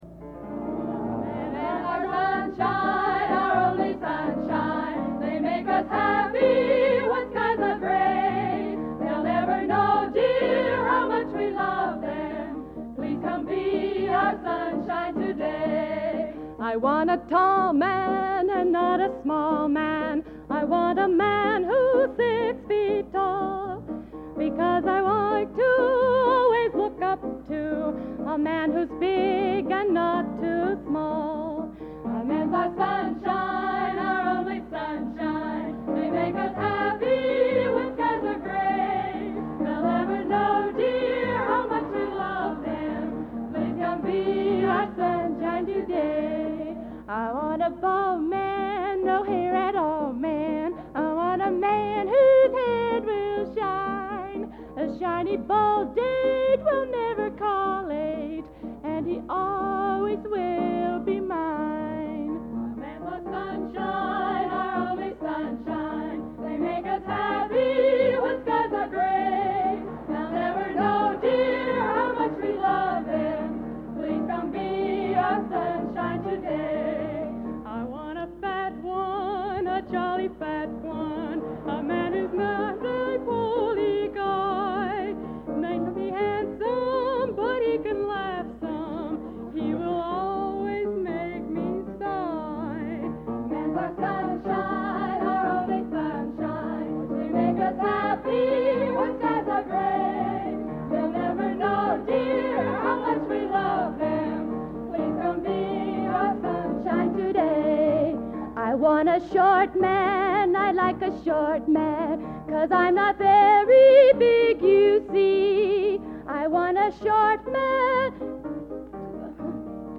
Genre: Popular / Standards | Type: